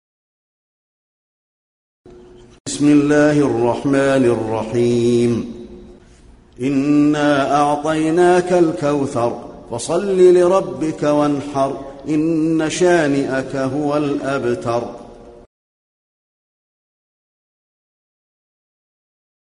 المكان: المسجد النبوي الكوثر